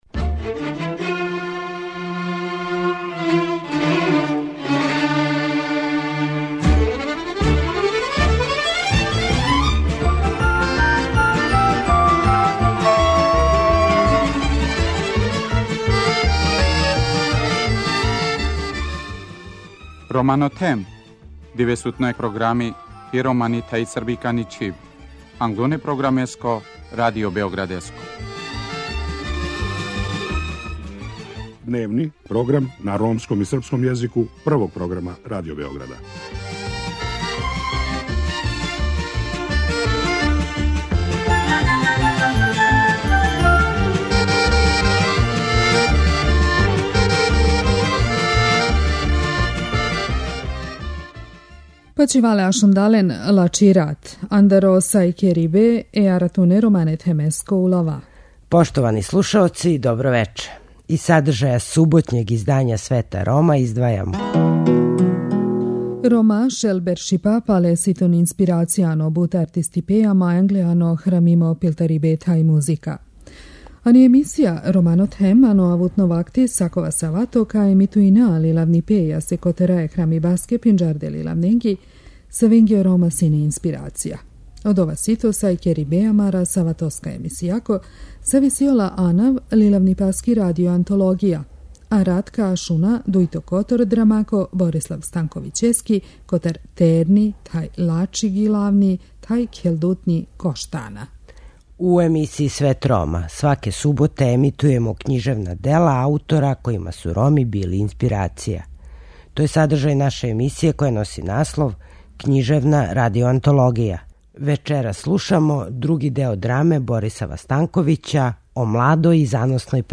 Вечерас слушамо други део драме Борисава Станковића о младој и заносној певачици и играчици Коштани.